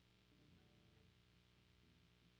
Guitar_011.wav